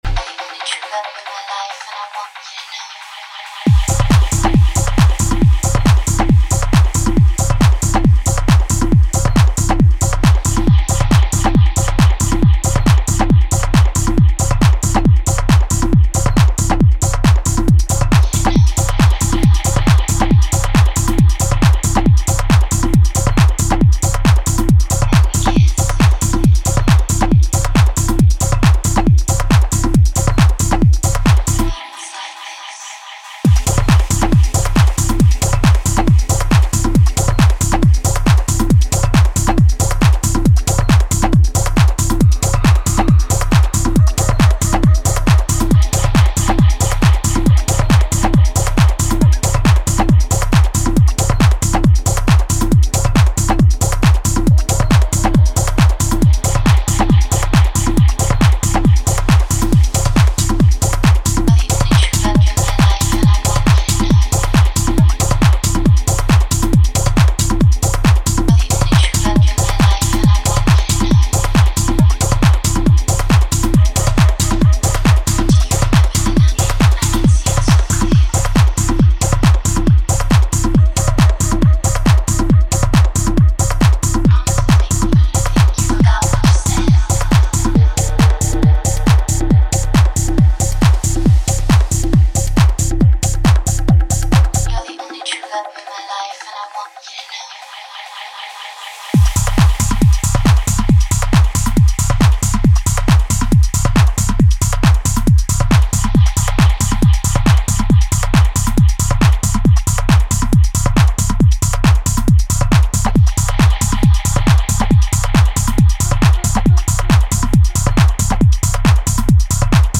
House Techno Trance